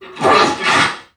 NPC_Creatures_Vocalisations_Robothead [21].wav